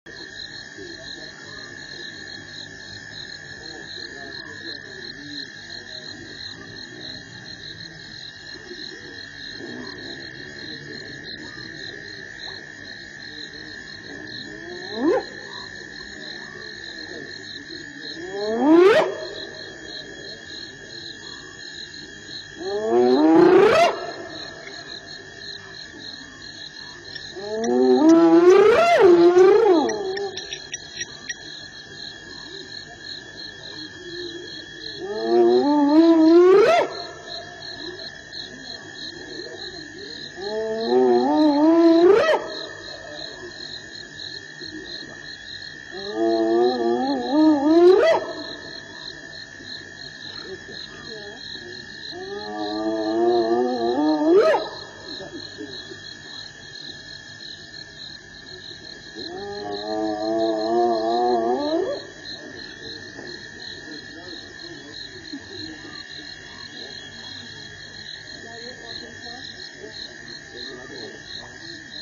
Hyenas calling.
hyena.mp3